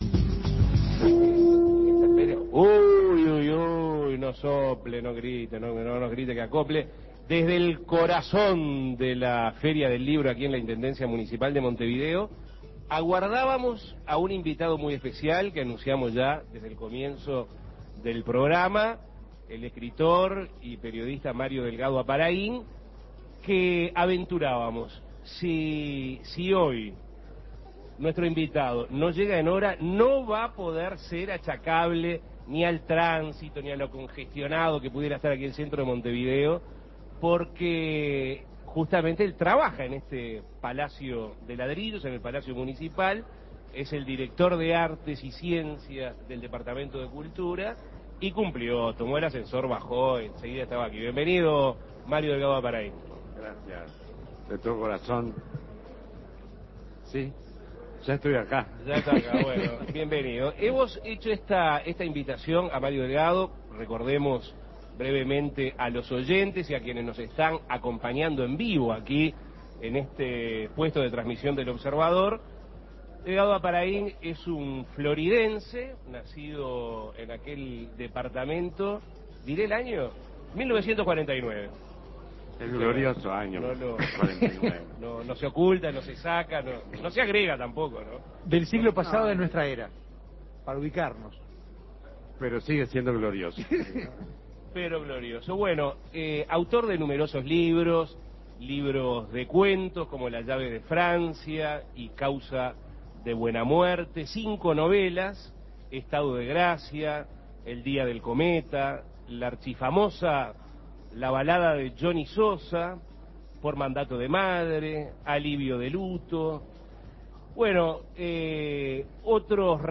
El escritor y periodista Mario Delgado Aparaín estuvo en el estudio de El Espectador de la Feria del Libro contando sobre esta experiencia.